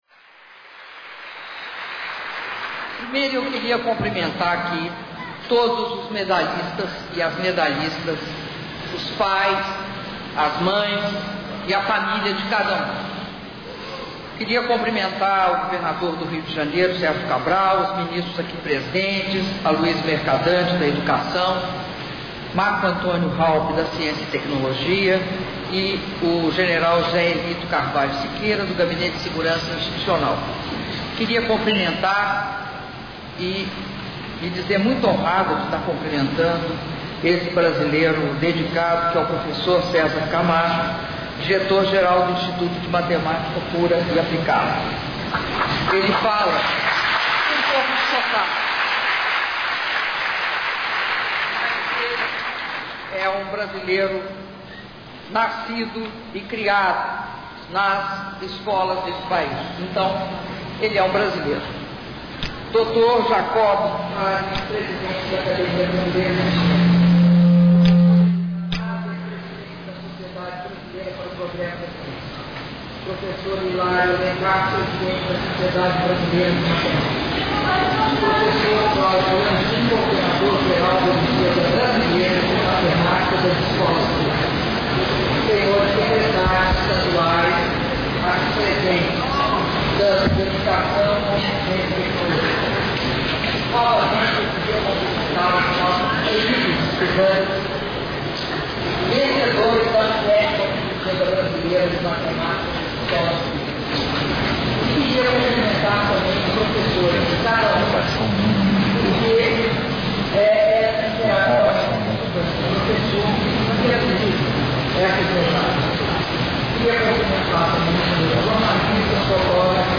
Discurso da Presidenta da República, Dilma Rousseff, durante a cerimônia nacional de premiação da 7ª Olimpíada Brasileira de Matemática das Escolas Públicas – Obmep/2011 - Rio de Janeiro/RJ